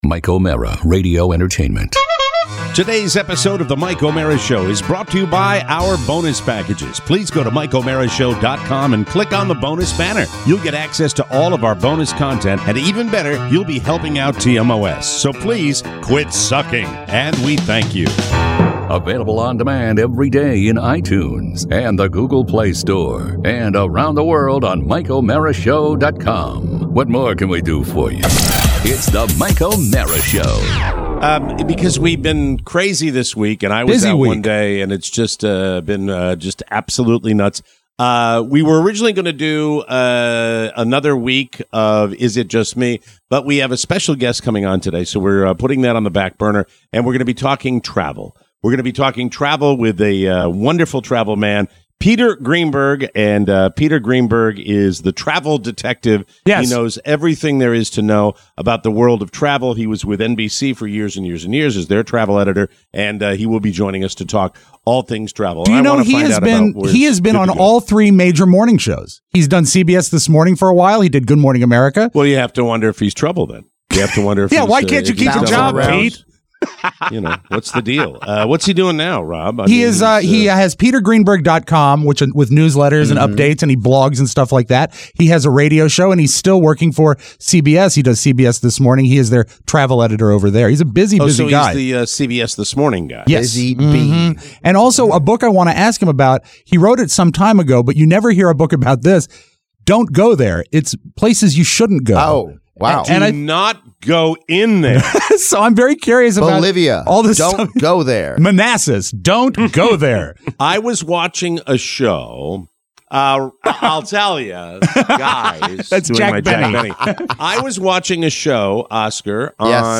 Today, a chat with travel expert Peter Greenberg! Playoff hockey! A CPAP impression!